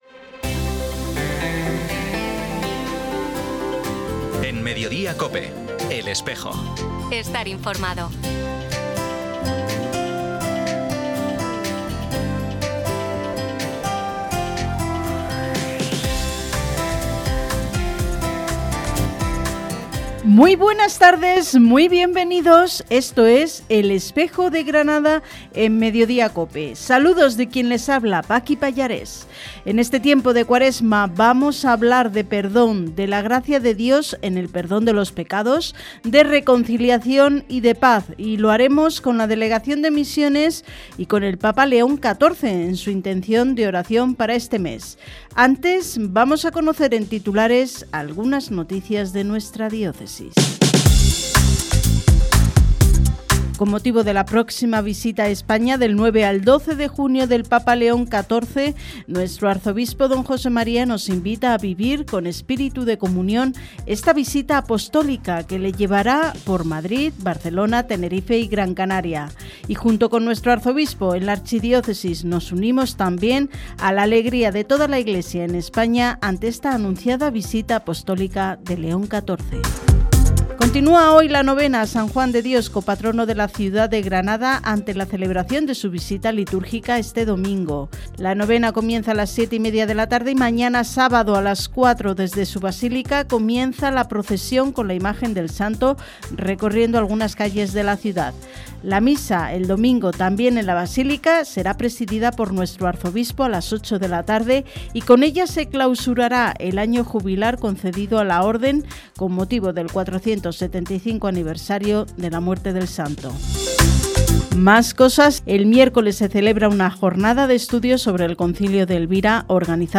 Programa emitido en COPE Granada.